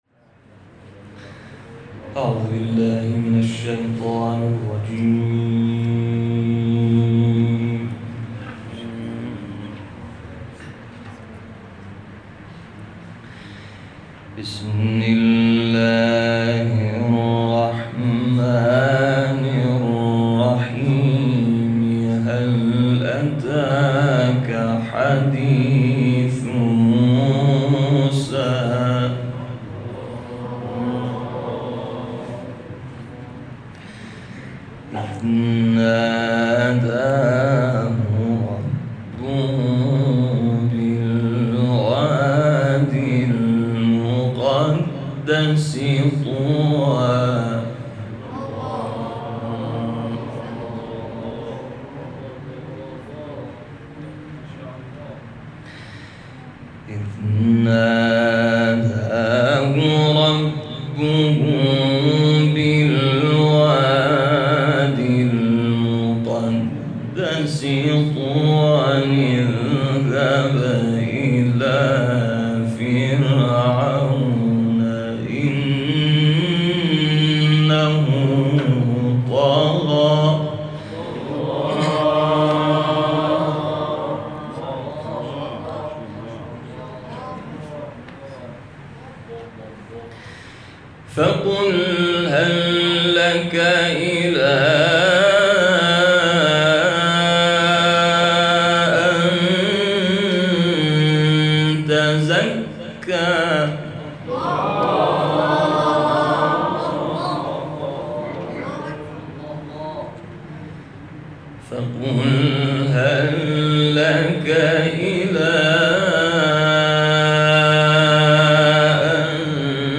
تلاوت آیاتی از سوره نازعات